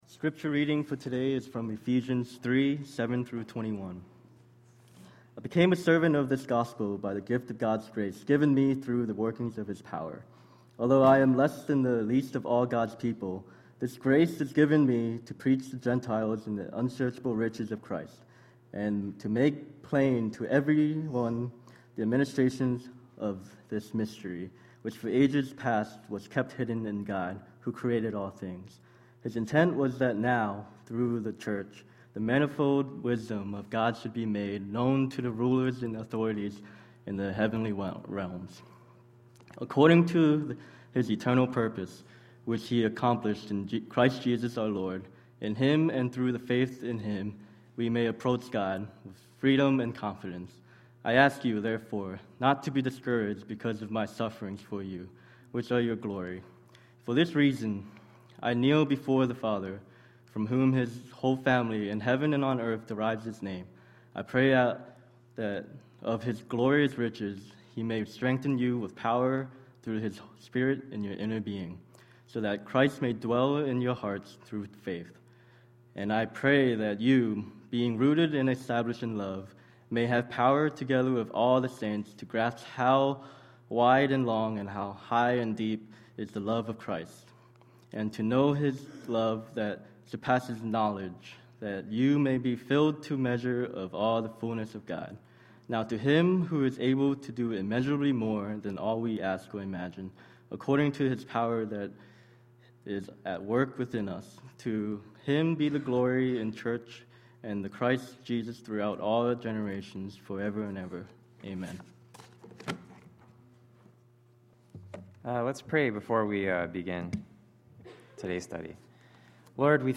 Sermon: October 10, 2010